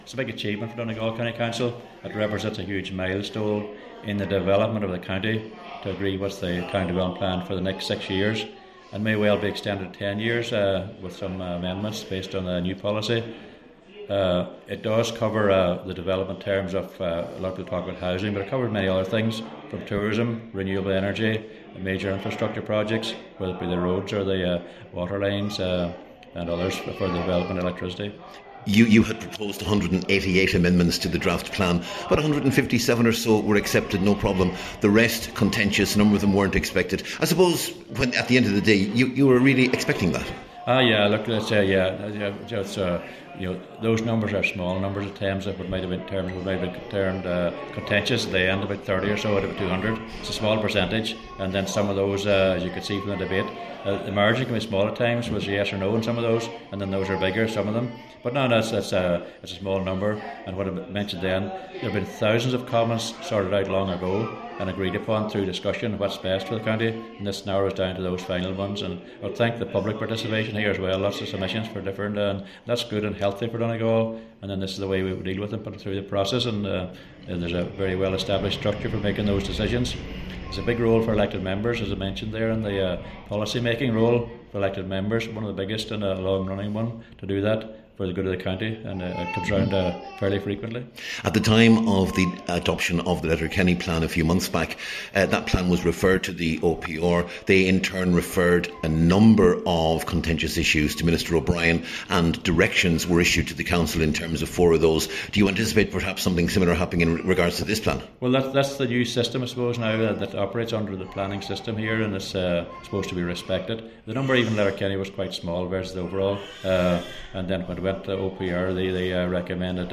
The Chief Executive of Donegal County Council is John McLaughlin: